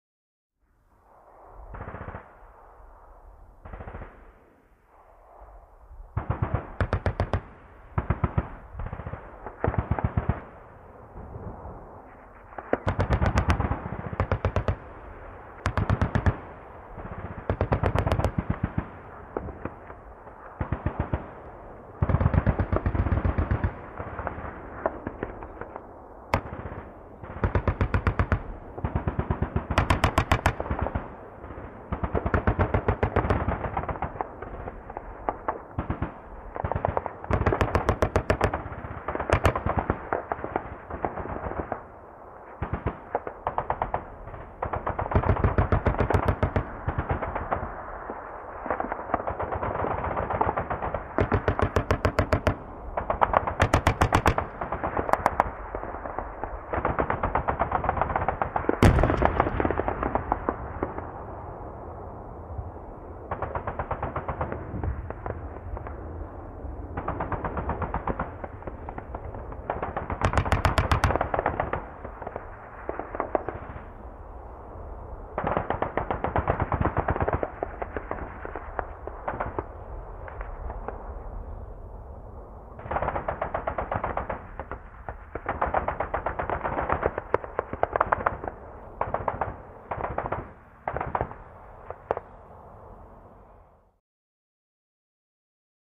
Artillery Battle
Artillery Battle Background; Intense Close Up And Distant Heavy Caliber Machine Gun Fire Throughout With Occasional Artillery Launch And An Explosion At The End.